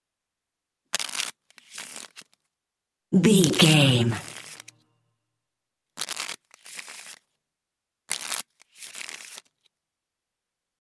Deck playing cards shuffle x4
Sound Effects
foley